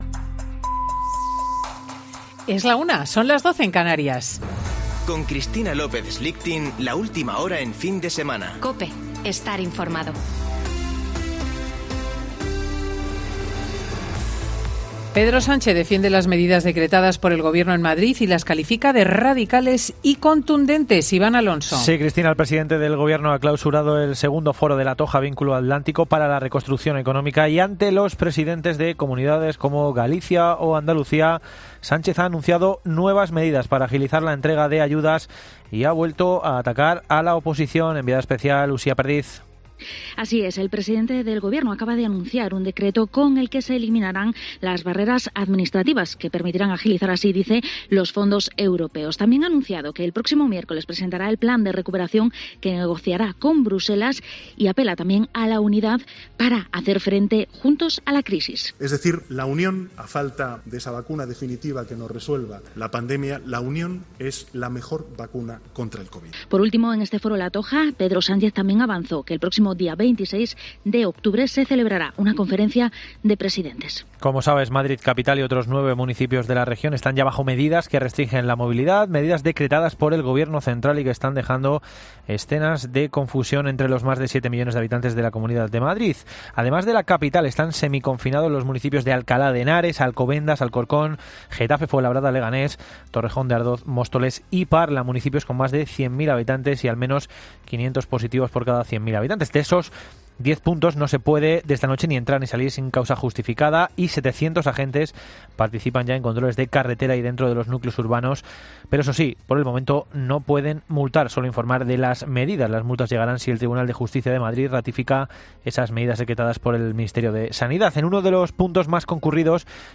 AUDIO: Boletín de noticias de COPE del 3 de Octubre de 2020 a las 13.00 horas